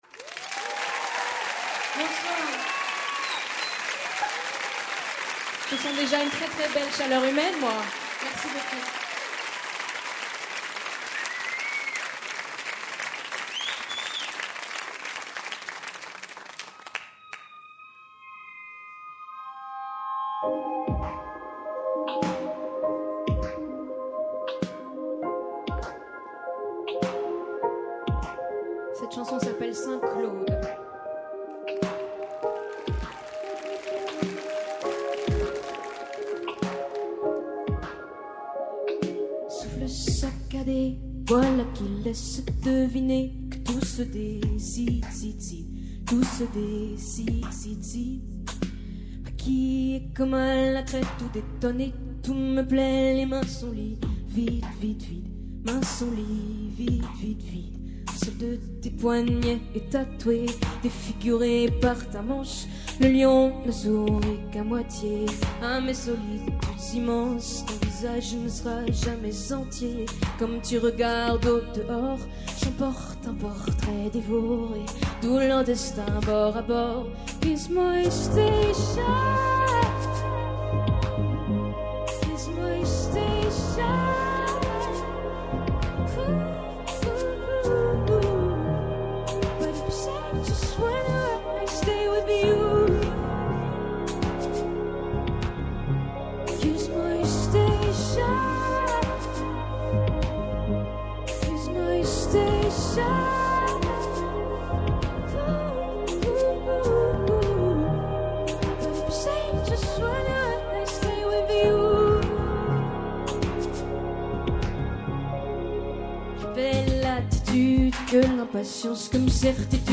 son album inédit en live au studio 105